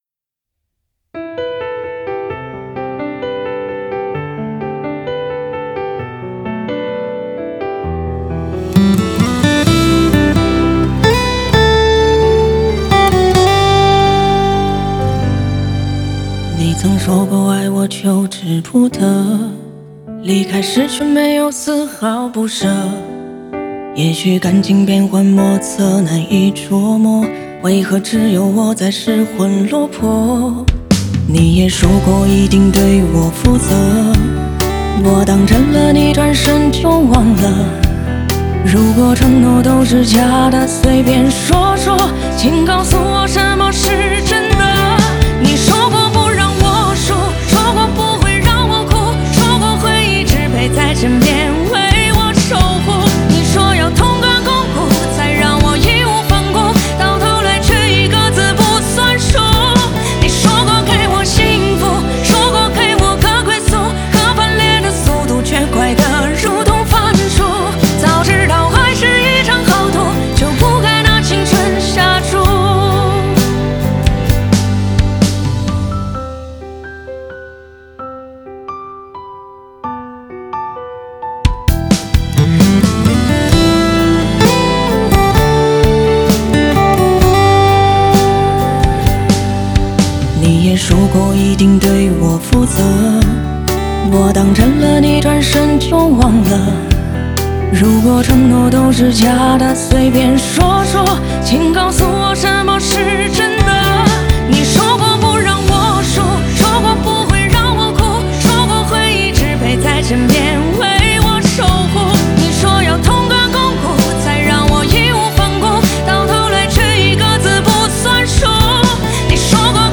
Ps：在线试听为压缩音质节选，体验无损音质请下载完整版
和声